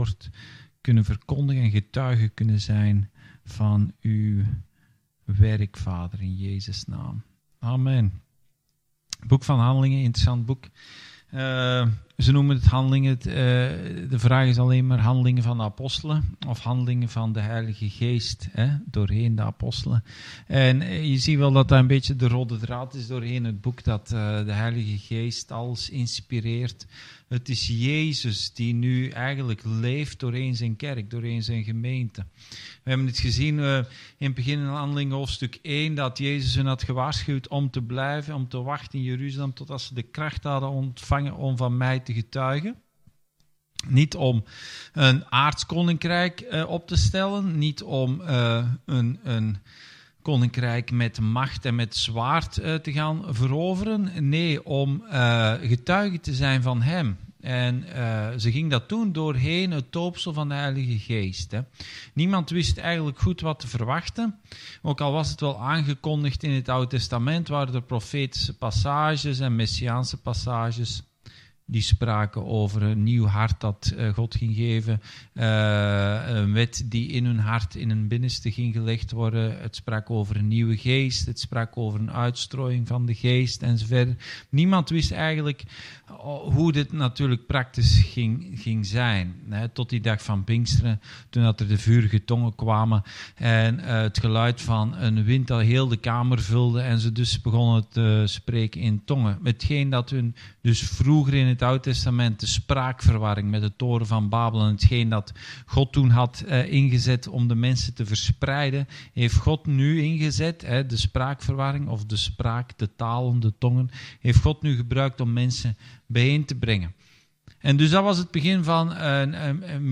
Bijbelstudie: Handelingen 3-4